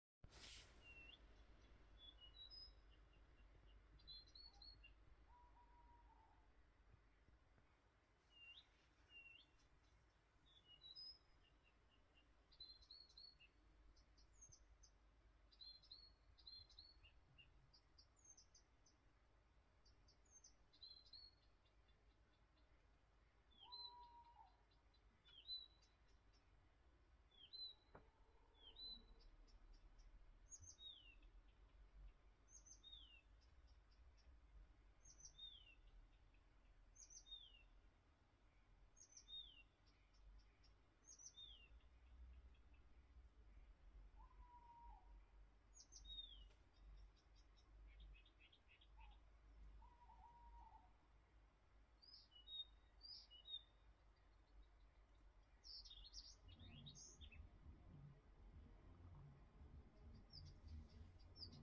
Krūmu ķauķis, Acrocephalus dumetorum
StatussDzied ligzdošanai piemērotā biotopā (D)
Galvenais dziedātājs ierakstā ir krūmu ķauķis - tiem tiešām ir raksturīgi tā tumsā dziedāt.